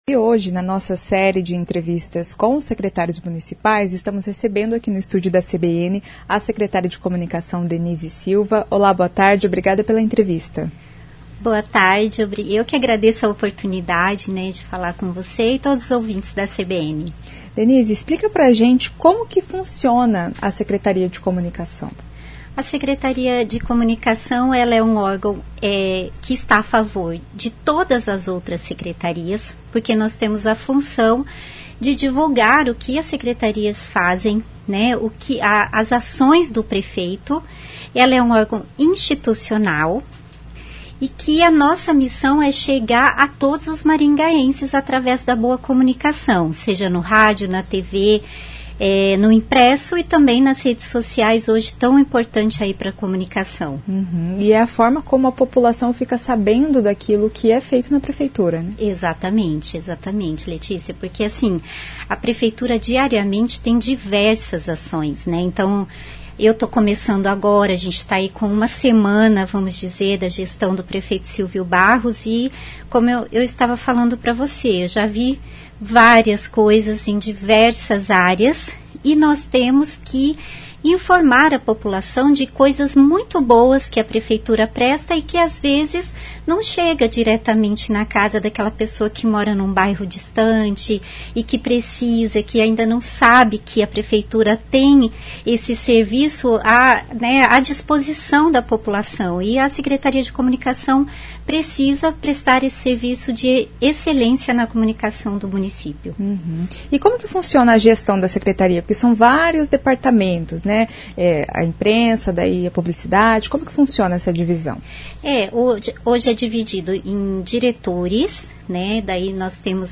Na Série de Entrevistas com os secretários municipais, Denise Silva, da Comunicação, explica como funciona a pasta.